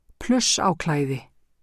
framburður